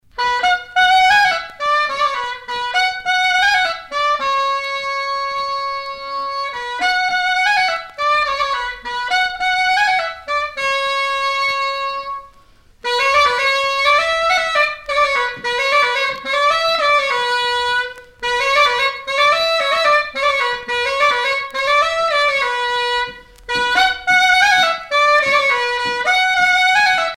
gestuel : à marcher
Sonneurs de clarinette
Pièce musicale éditée